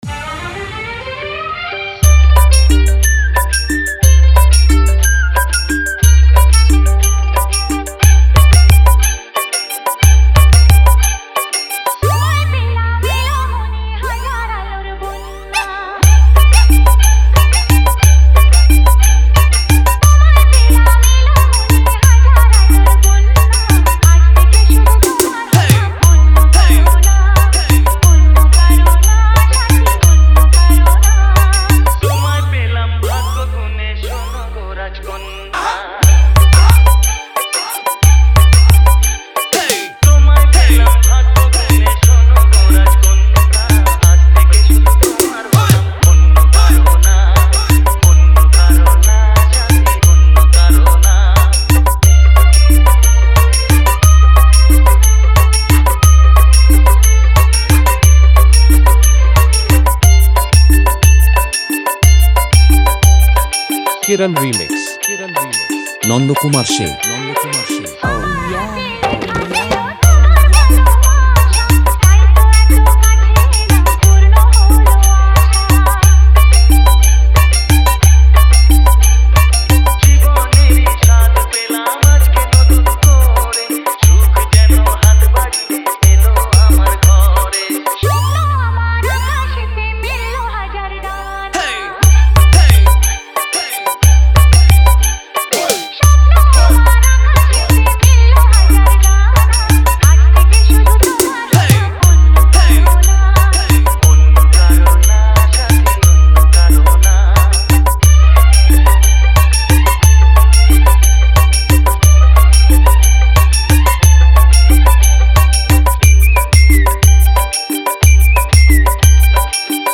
Bengali Humbing Dance Mix